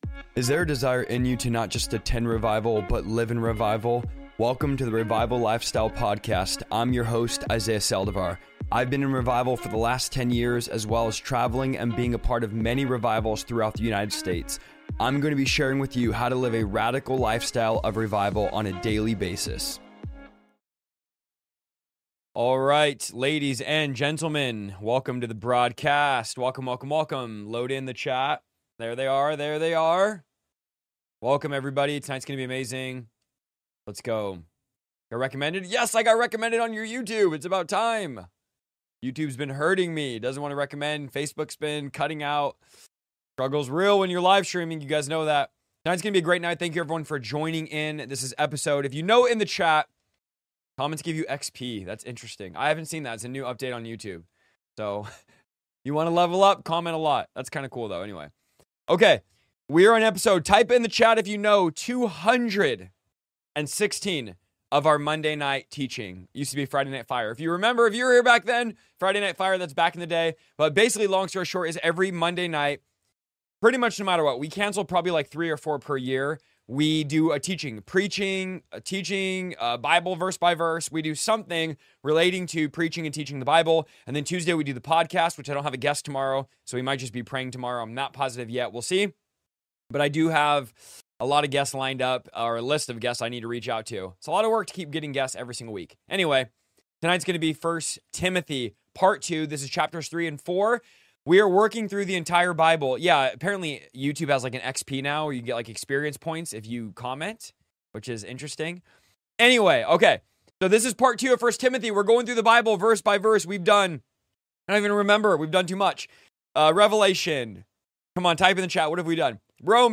In this powerful verse-by-verse teaching through 1 Timothy chapters 3 and 4, we uncover God's blueprint for church leadership—and Paul's urgent warning about a coming great falling away from the faith. Chapter 3 outlines the high calling and qualifications for bishops and deacons, revealing what true spiritual leadership looks like in a world full of compromise.